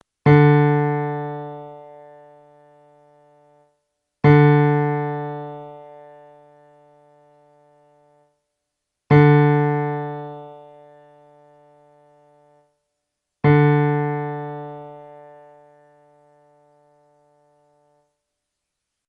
Wenn Sie auf die folgenden Links klicken, hören Sie, wie die Saiten klingen und können ihre Violoncello danach stimmen:
D-Saite (mp3):
cello_d.mp3